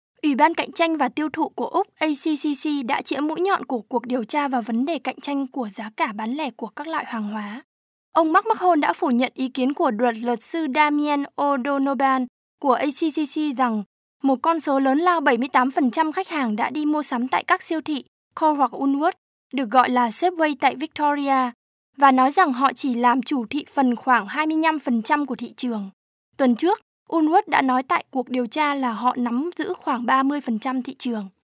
Professionelle Sprecherin für Werbung, TV, Radio, Industriefilme und Podcasts aus Nord-Vietnam. Professional female voice over artist from North Vietnam.
Sprechprobe: Werbung (Muttersprache):
female vietnamese voice over artist